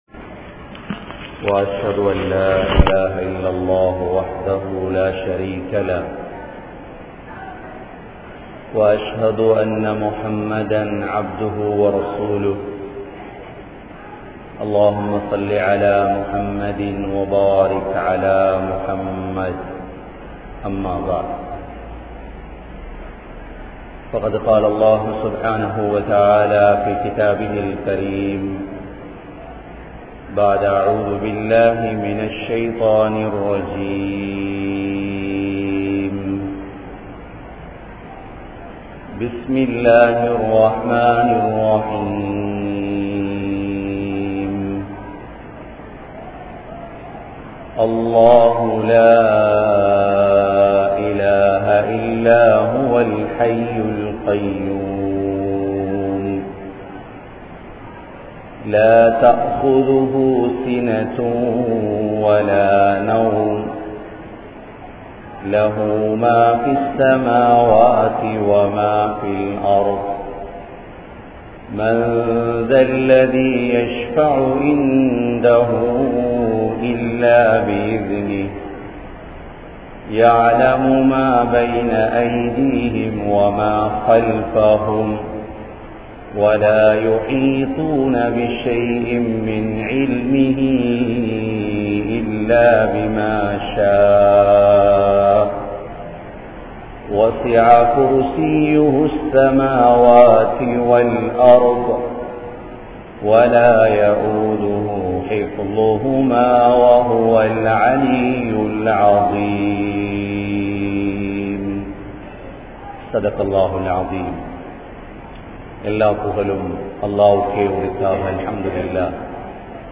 Allah`vin Aatsi (அல்லாஹ்வின் ஆட்சி) | Audio Bayans | All Ceylon Muslim Youth Community | Addalaichenai